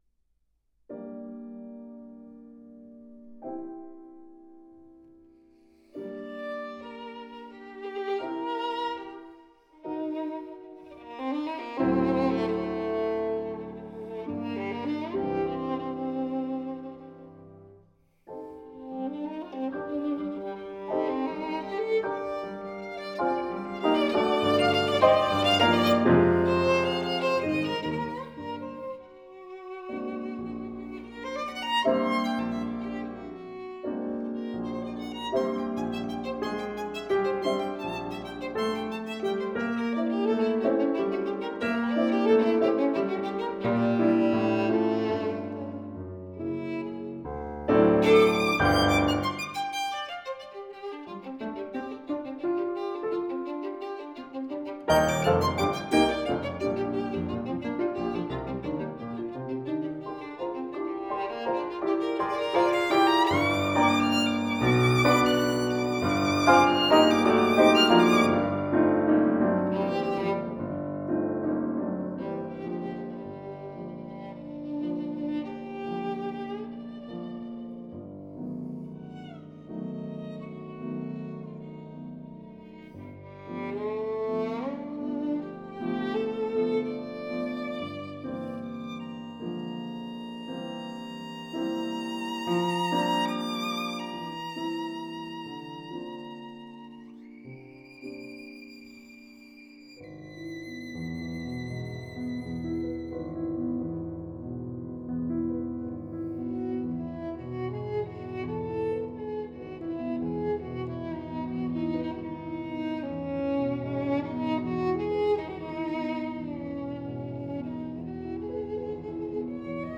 violinist
02-Sonata-for-Violin-and-Piano-in-G-Minor-L.-148_-I.-Allegro-Vivo.m4a